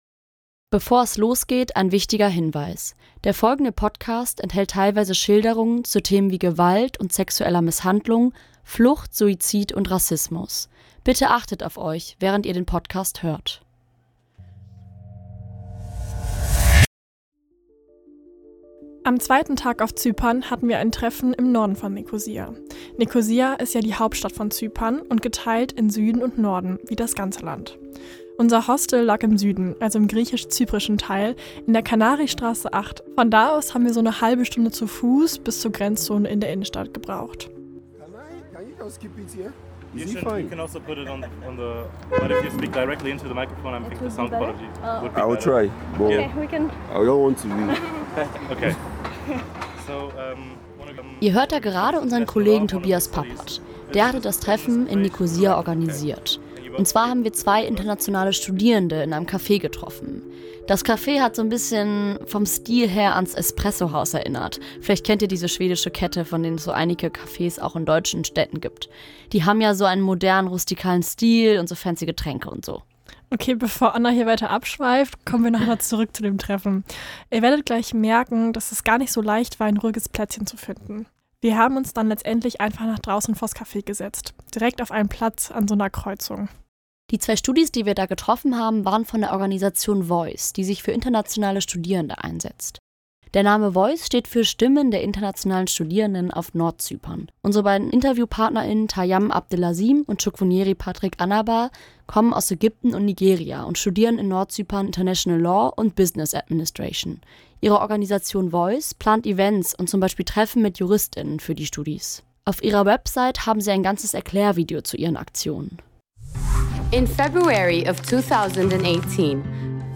In der zweiten Folge des Podcasts beleuchten wir die falschen Versprechen, mit denen internationale Studierende nach Nordzypern gelockt werden. Durch Gespräche mit Studierenden und Profs erfahren wir mehr über die Situation, in die viele junge Menschen geraten und auch über die Qualität der Bildung im Nordteil der Insel.